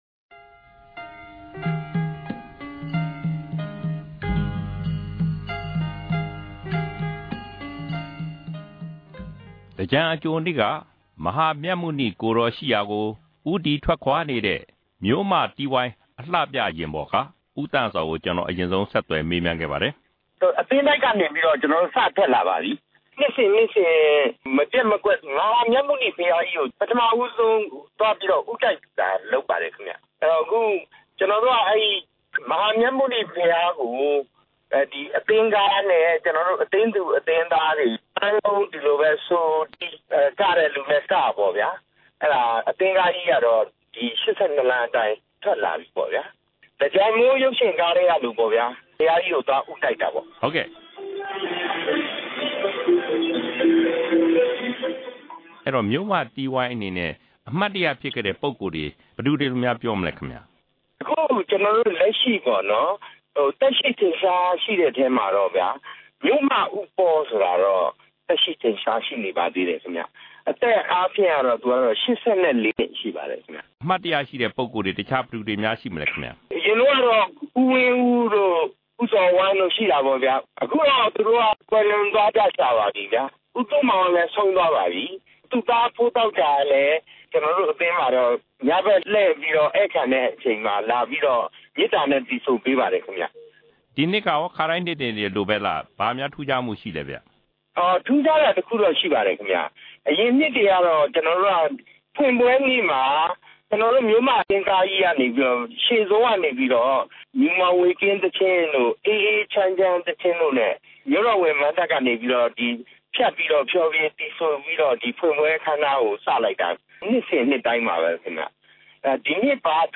ဒီအပတ် တနင်္ဂနွေ စကားဝိုင်းမှာ မန္တလေးမြို့မ တီးဝိုင်းနဲ့ လူရွှင်တော် ပါပါလေးတို့အဖွဲ့တွေရဲ့ မဟာသြင်္ကန် ပါဝင်ဆင်နွဲနေကြပုံတွေကို နားဆင်ရမှာဖြစ်ပါတယ်။